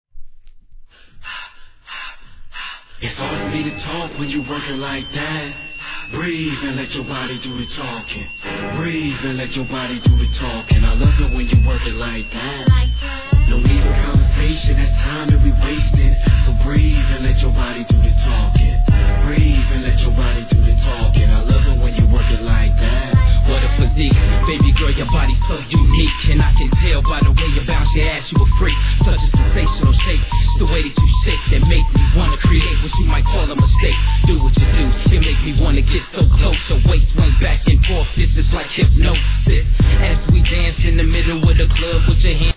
HIP HOP/R&B
バスドラがズンズン、緊迫感を煽るような息遣いのサンプリングとう青とが危ないトッラク上で